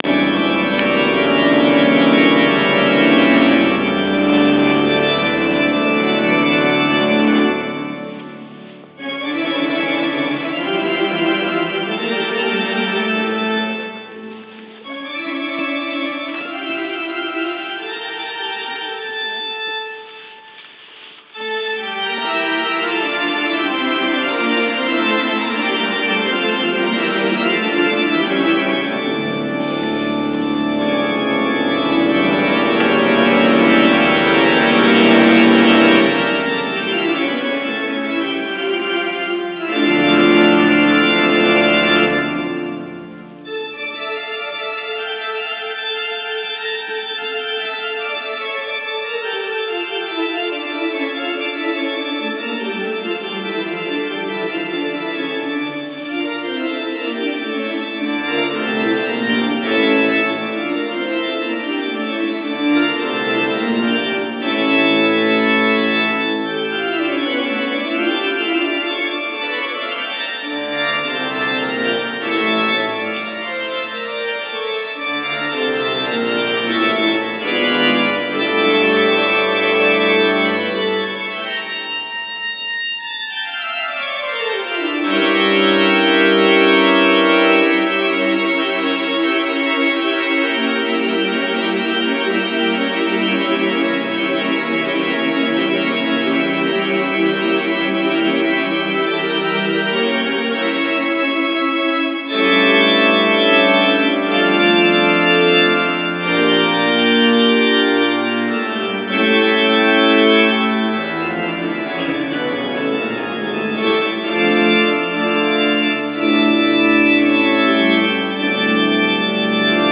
Die Orgel während des Balletts / The Organ During the Ballet